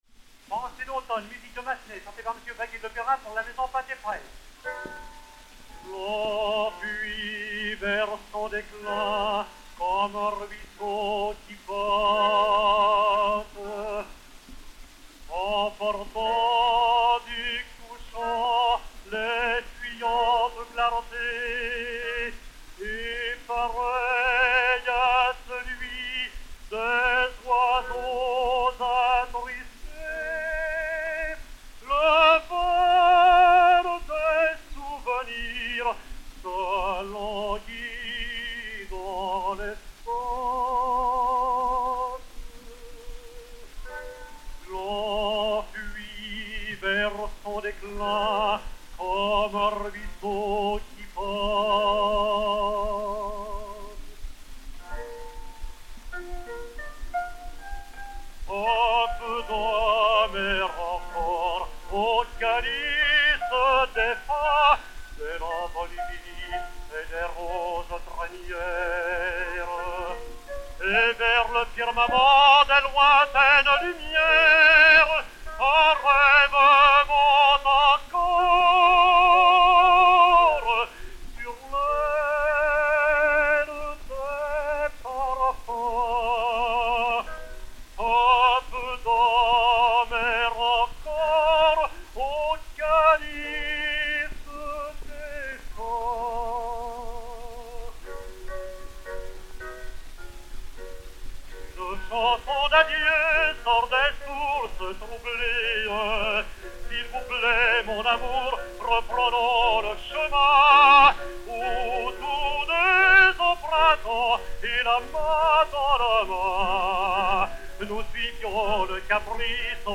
Albert Vaguet, ténor, avec piano